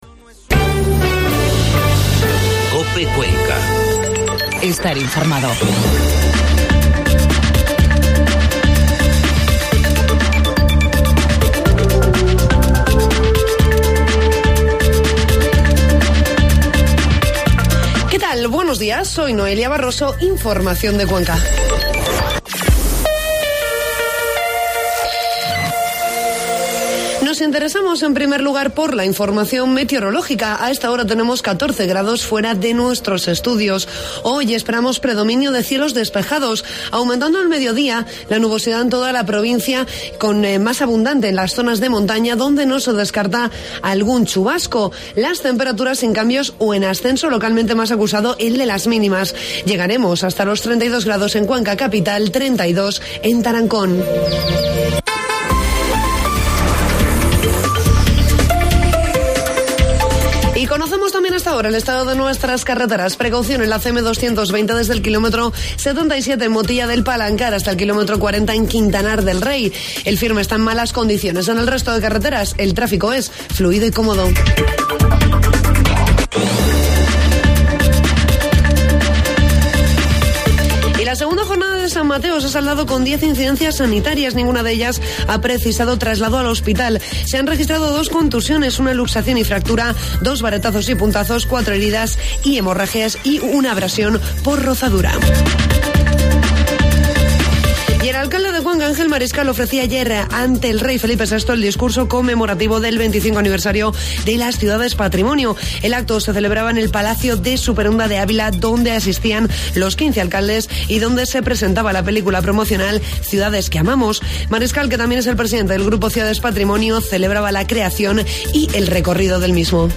El alcalde de Cuenca ha pronunciado ante el Rey el discurso del acto del Grupo Ciudades Patrimonio